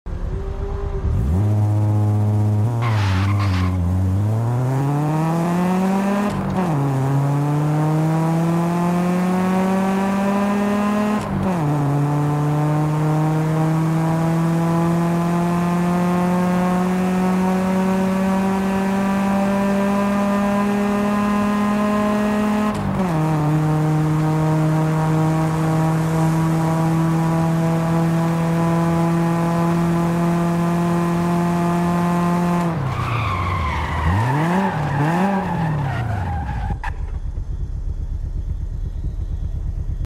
2010 Nissan Tsuru Launch Control sound effects free download
2010 Nissan Tsuru Launch Control & Sound - Forza Horizon 5